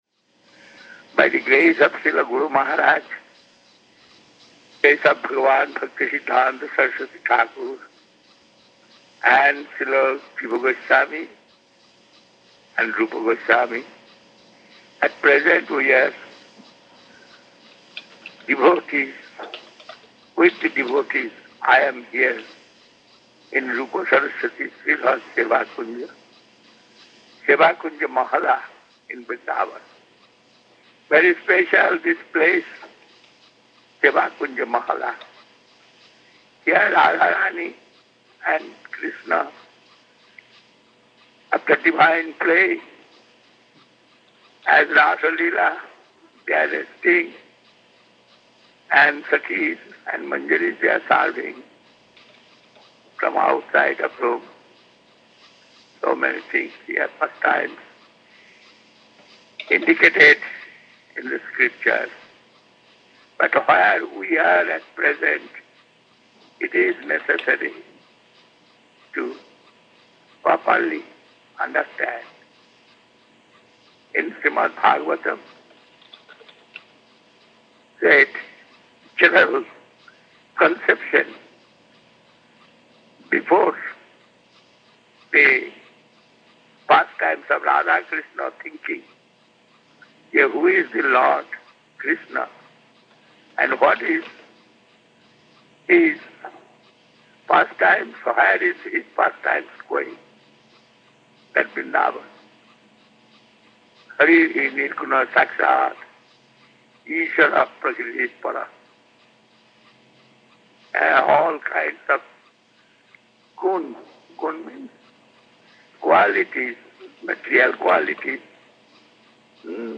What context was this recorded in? Place: SCSMath & Mission Vrindavan